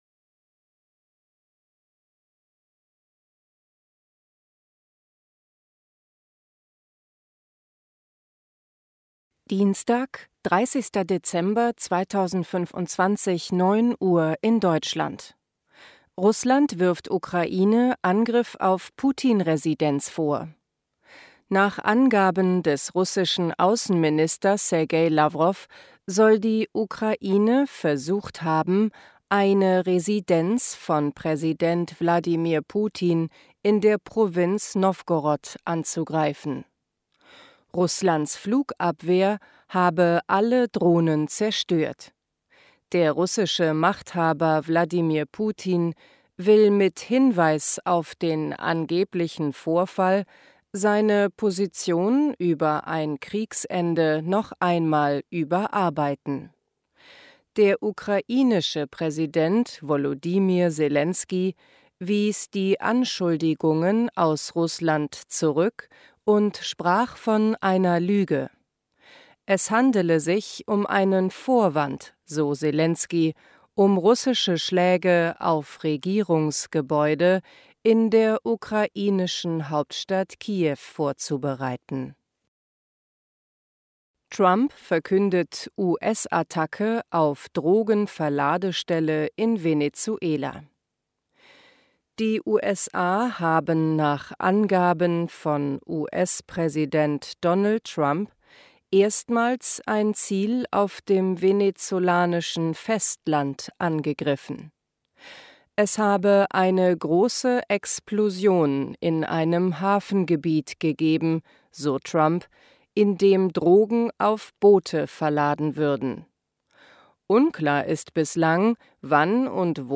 30.12.2025 – Langsam Gesprochene Nachrichten
Trainiere dein Hörverstehen mit den Nachrichten der DW von Dienstag – als Text und als verständlich gesprochene Audio-Datei.